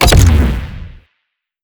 Active_reload_fail.wav